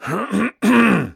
Throat1.wav